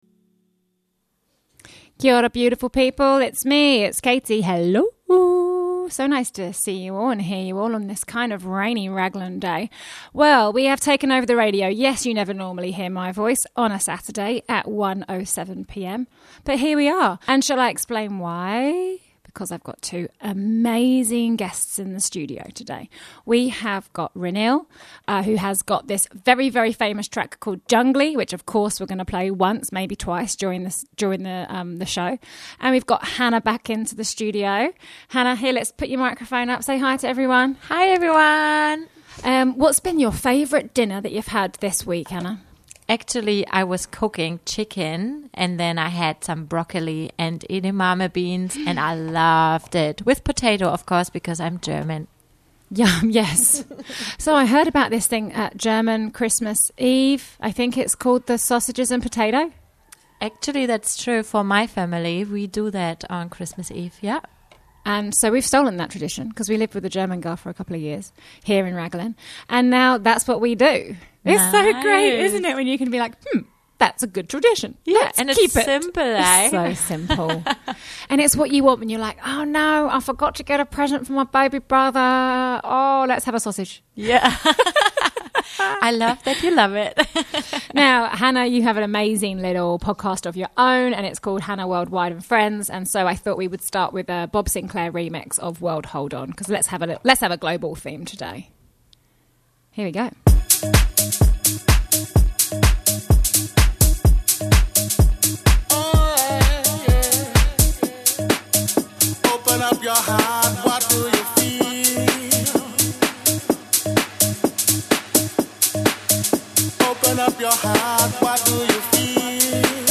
Pour yourself a cuppa and listen-in to this Raglan Community Radio Saturday afternoon podcast.